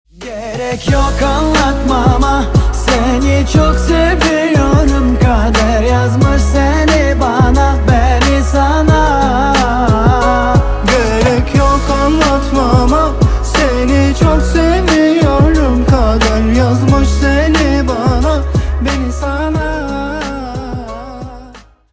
восточные на казахском